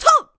FSA_Link_SwordSlash311.9 KBMono, 16 KHz
FSA_Link_SwordSlash3.wav